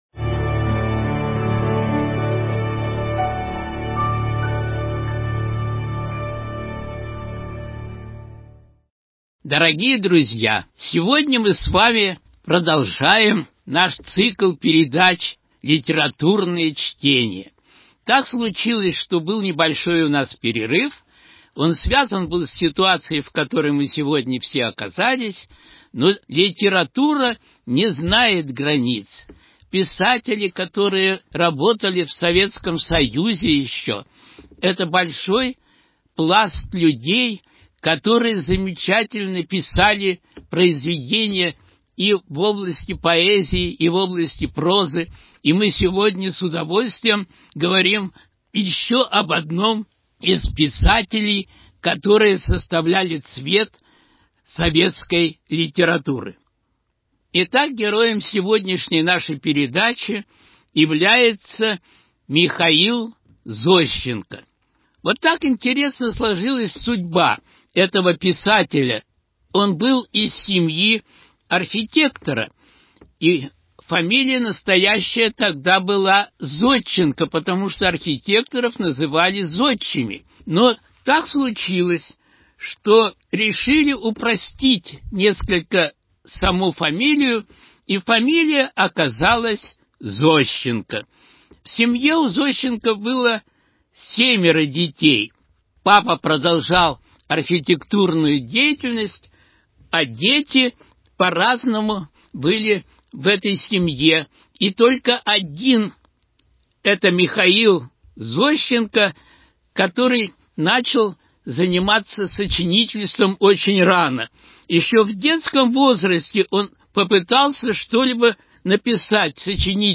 Литературные чтения